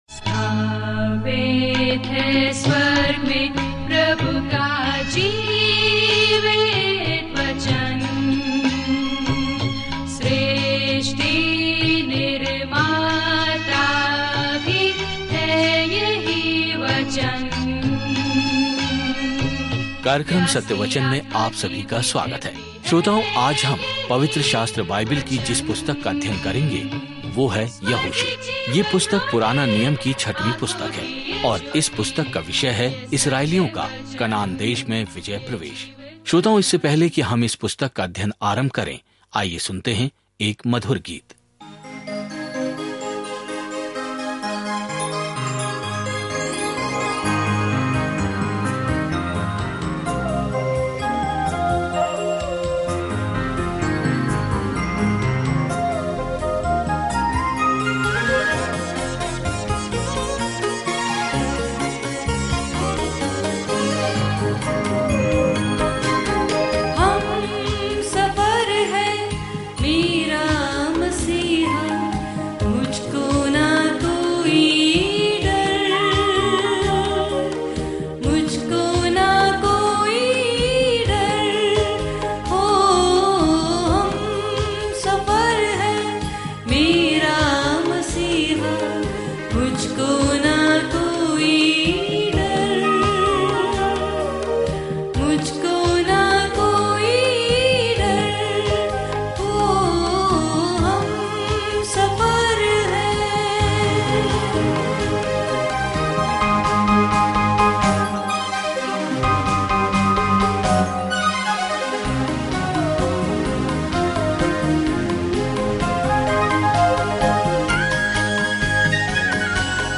पवित्र शास्त्र यहोशू 1:1-11 यह योजना प्रारंभ कीजिए दिन 2 इस योजना के बारें में आइए यहोशू की पुस्तक को "निर्गमन: भाग दो" कहें, क्योंकि परमेश्वर के लोगों की एक नई पीढ़ी उस भूमि पर कब्जा कर लेती है जिसका उसने उनसे वादा किया था। यहोशू के माध्यम से दैनिक यात्रा करें क्योंकि आप ऑडियो अध्ययन सुनते हैं और भगवान के वचन से चुनिंदा छंद पढ़ते हैं।